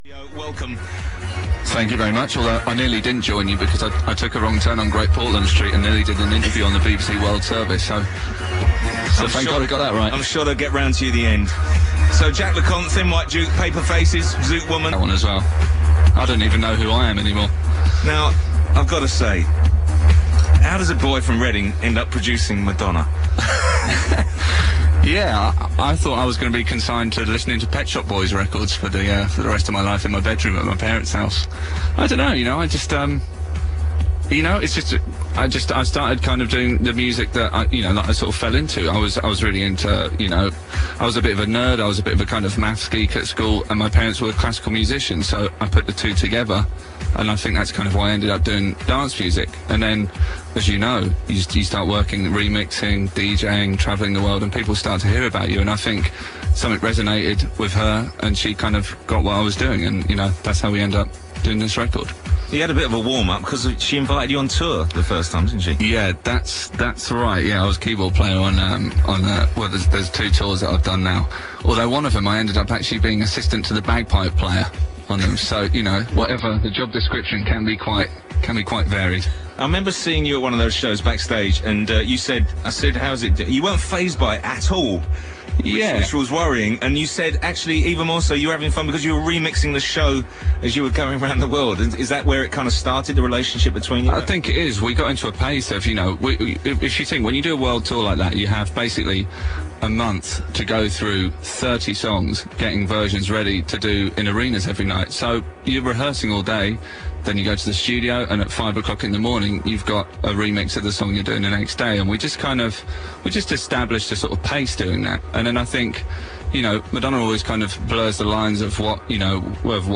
On October 14 2005, Stuart Price was interviewed on BBC Radio 1 to promote the releases of Madonna’s Hung Up single and the Confessions On A Dance Floor album.
stuart-price-radio-1-interview-confessions-2005.mp3